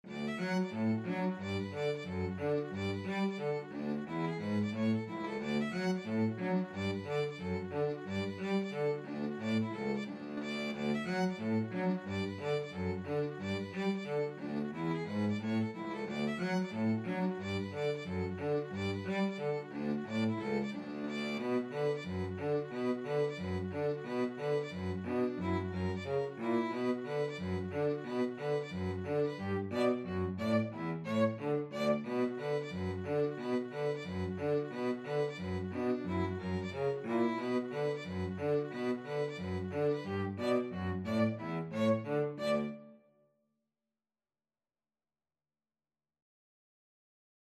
Free Sheet music for Violin-Cello Duet
D major (Sounding Pitch) (View more D major Music for Violin-Cello Duet )
2/2 (View more 2/2 Music)
=90 Fast two in a bar
Traditional (View more Traditional Violin-Cello Duet Music)